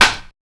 134TTCLAP1-R.wav